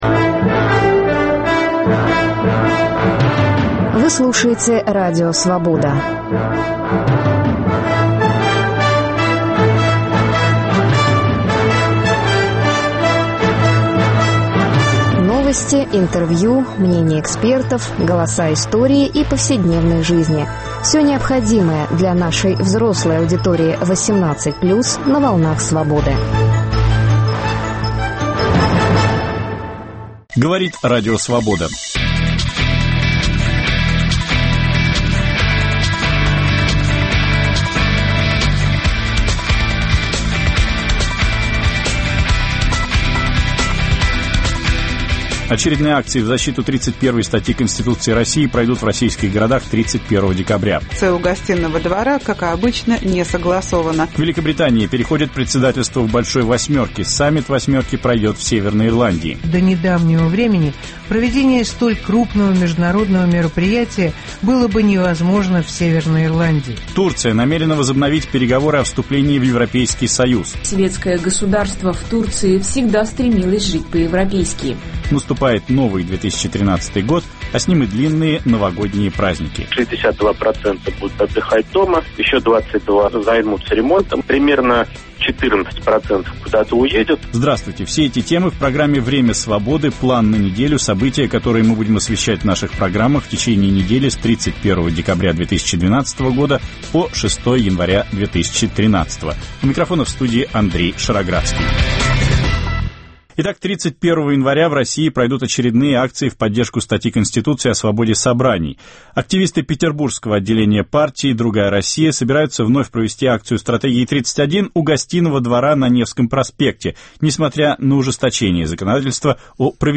Программу попеременно ведут редакторы информационных программ в Москве и Праге.